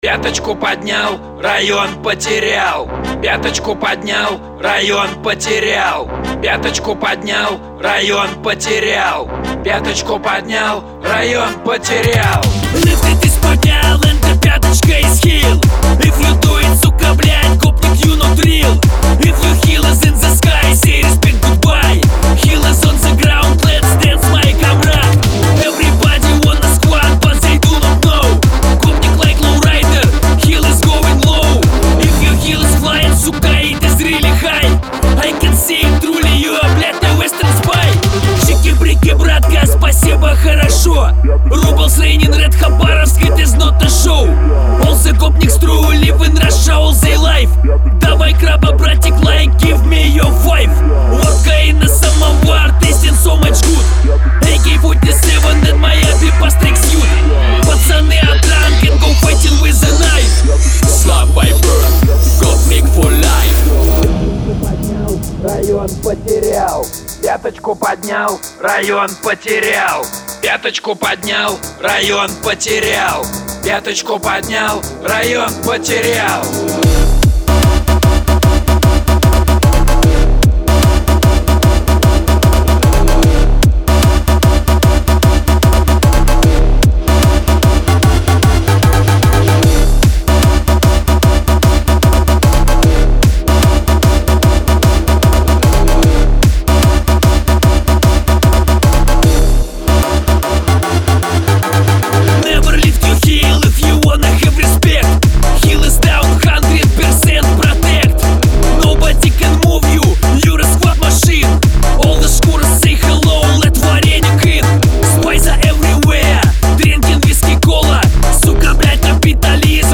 hardbass